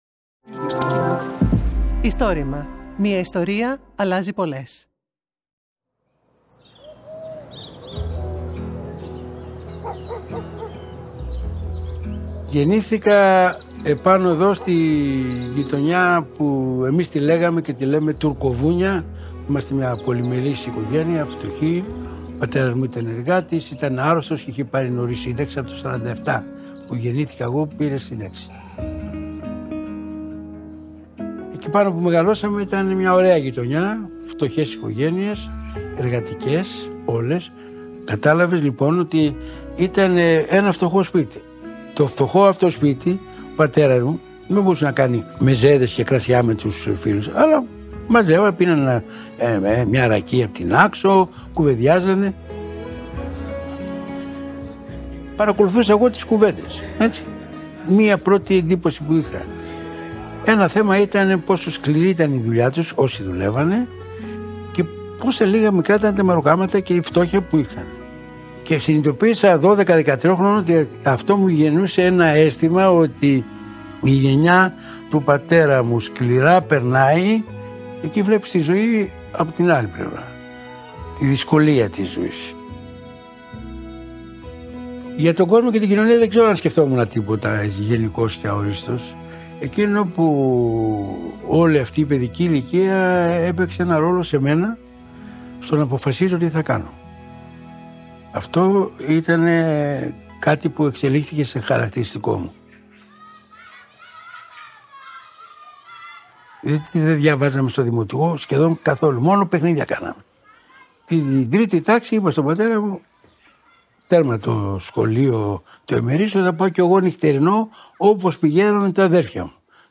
Το Istorima είναι το μεγαλύτερο έργο καταγραφής και διάσωσης προφορικών ιστοριών της Ελλάδας.